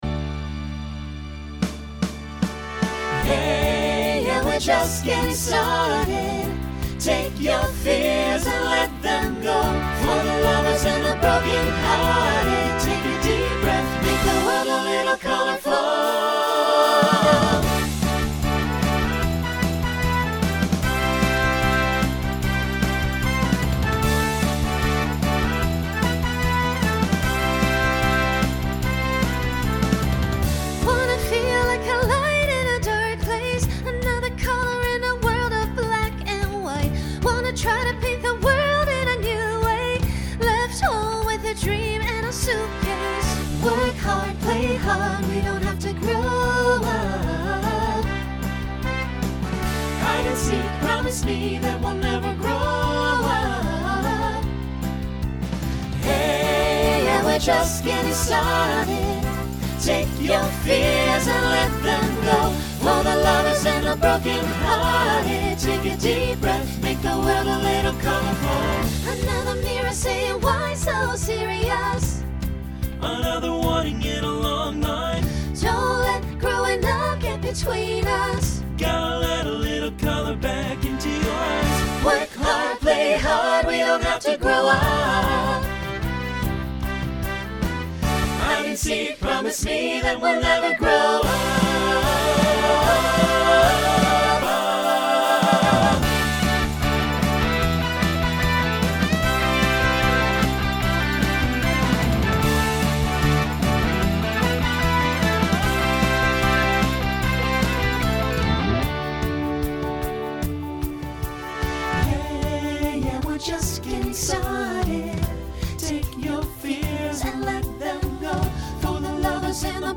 New SSA Voicing for 2026.